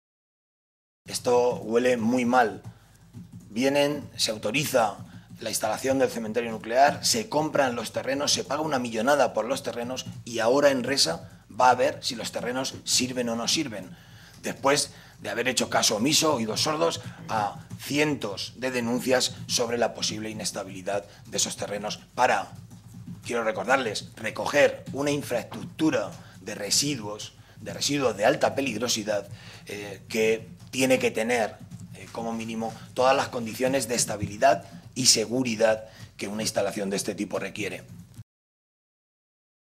Modesto Belinchón, portavoz de Industria y Energía del Grupo Parlamentario Socialista
Cortes de audio de la rueda de prensa